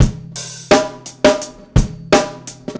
Badurim 2 86bpm.wav